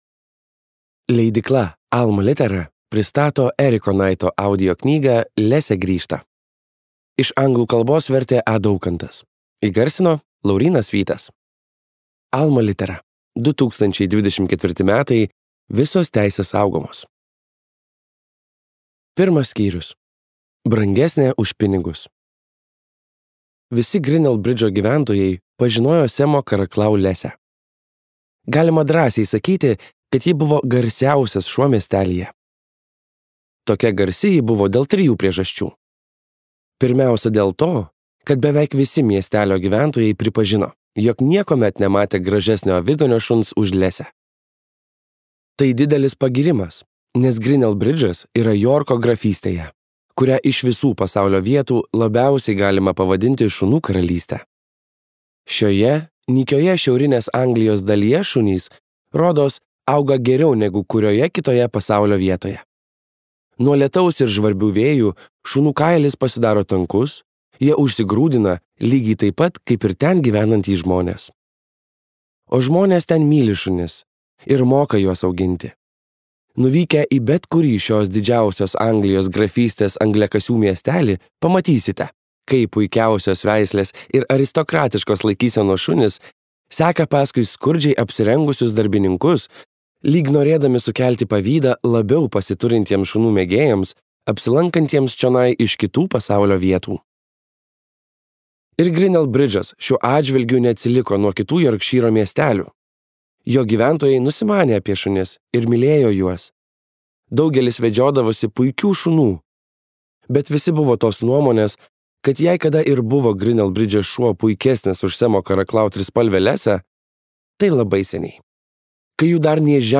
Lesė grįžta | Audioknygos | baltos lankos